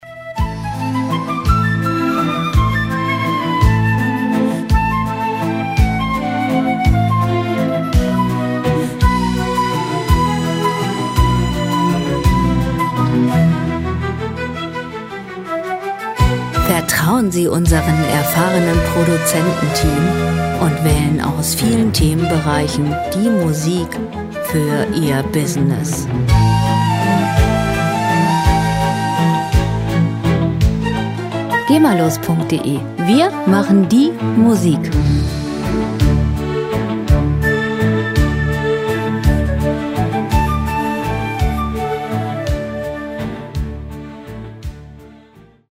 Klassik Pop
Musikstil: Classical Crossover
Tempo: 168 bpm
Tonart: A-Moll
Charakter: lebendig, verführerisch
Instrumentierung: Orchester, E-Bass, Drums, Querflöte